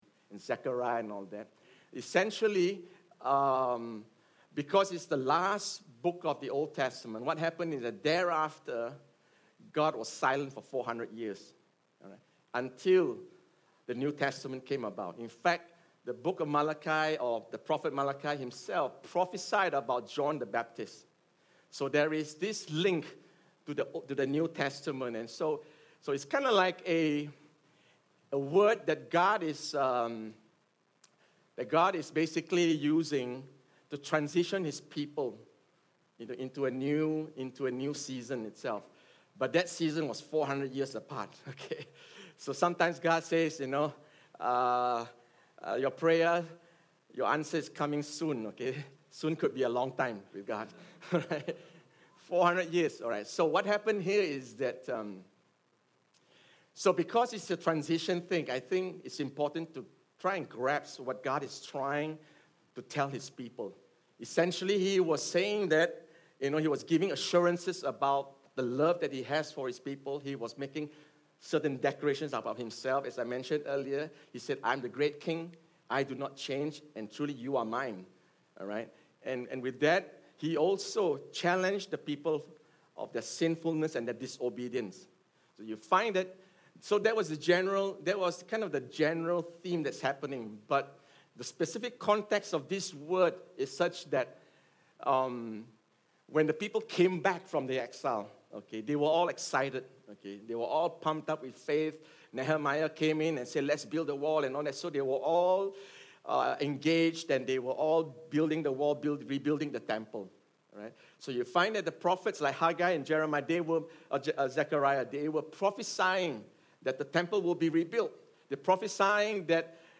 Sermon_RememberThis_26Jun19.mp3